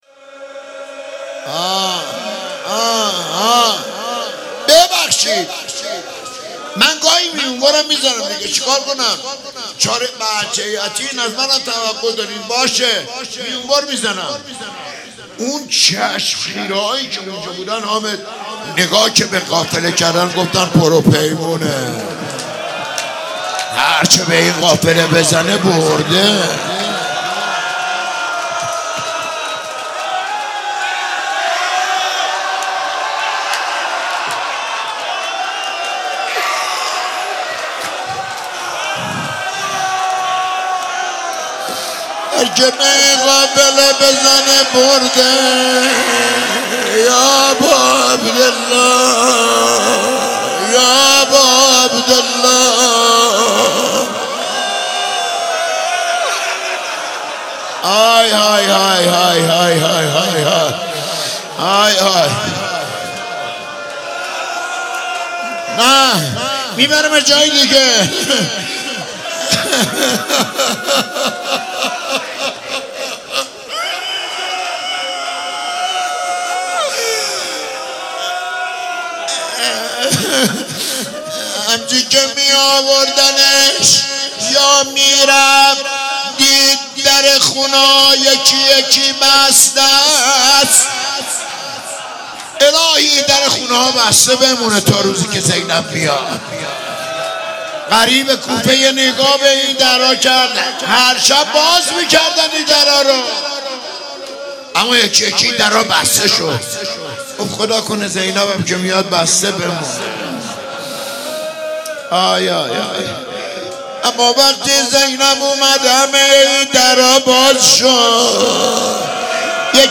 شهادت امام باقر علیه السلام - روضه
مداحی